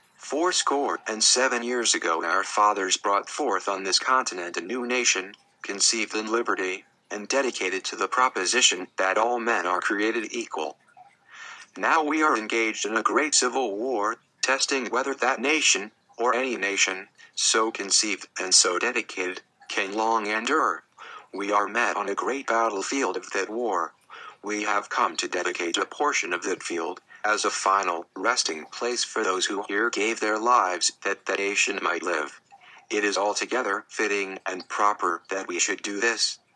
Alex voice makes it much more listenable (notice that it even takes breaths and stumbles over some words to emulate a human reader)); that many computers cannot already do.